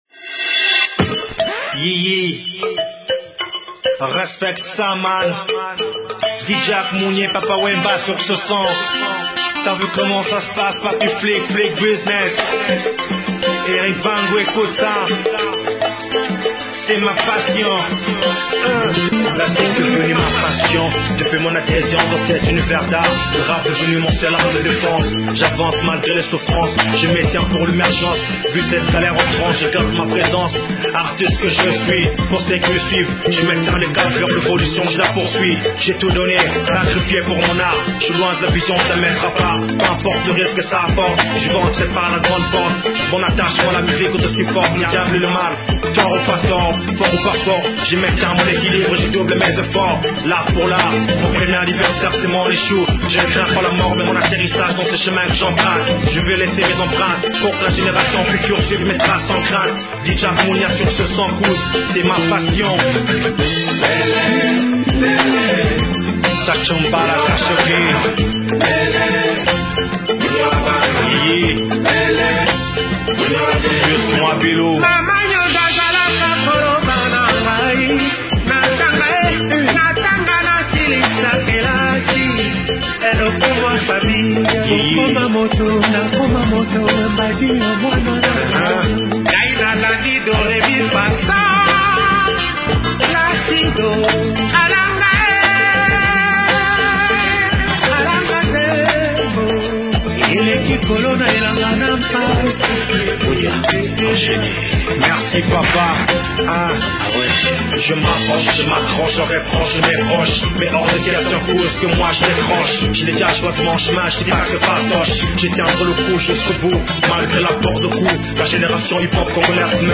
chanson hautement métissé
jeune rappeur congolais
un mélange du Mutuashi kasaïen et du rap Kinois